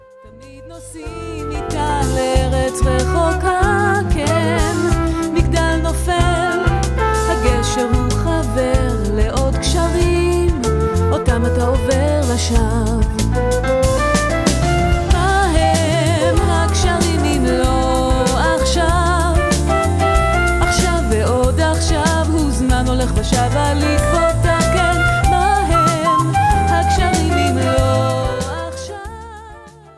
Sensuous female vocals